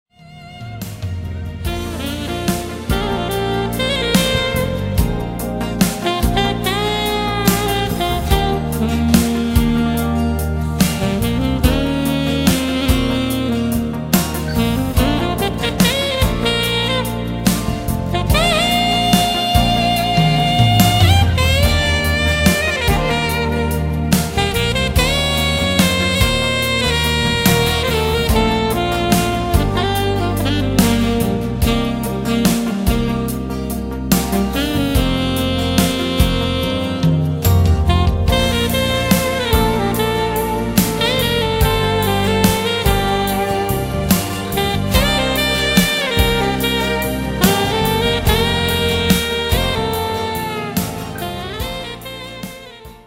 (SOPRANO, ALTO & TENOR SAXES)
(BASS)
(ACUSTIC & ELECTRIC GUITARS)
DRUM& PERCUSSION PROGRAMMING)